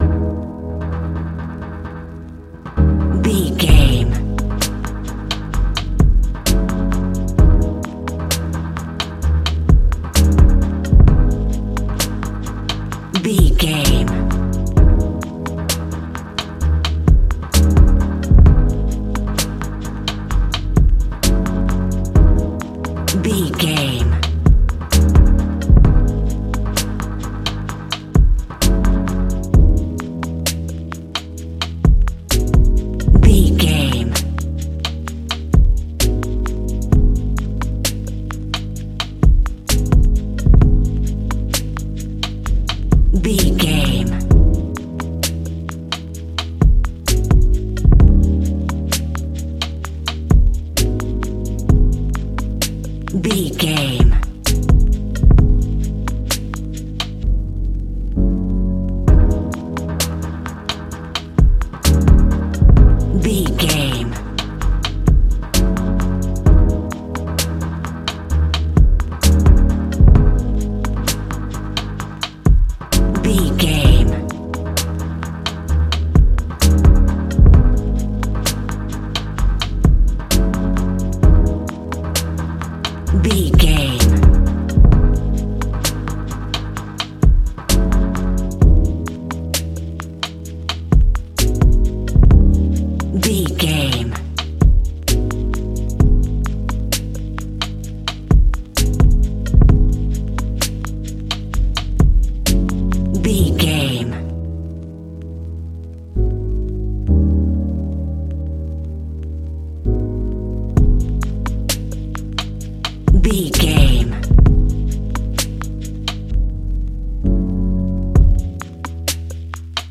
Ionian/Major
C♯
chilled
laid back
Lounge
sparse
new age
chilled electronica
ambient
atmospheric
instrumentals